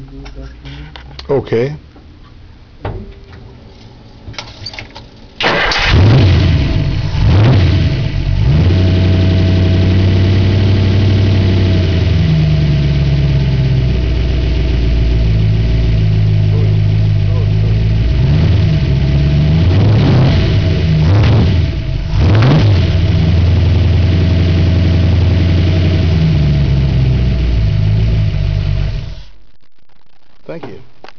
Start her up